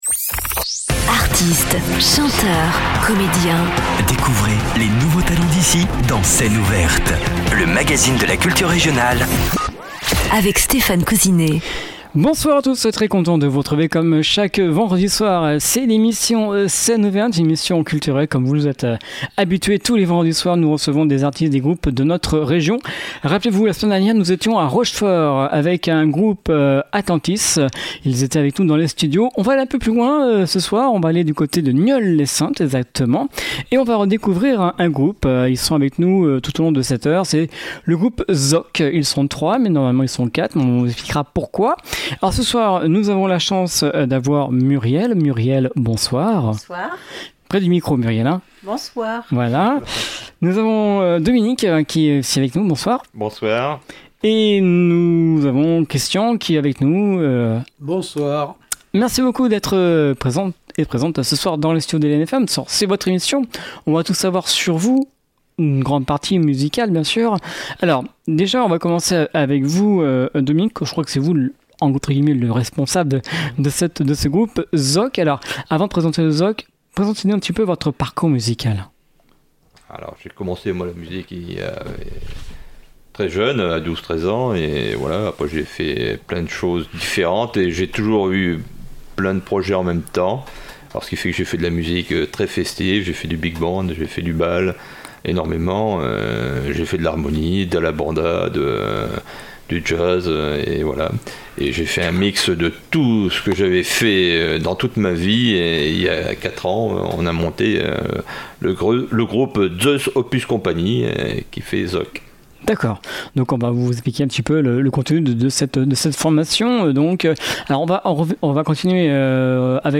orchestre de jazz festif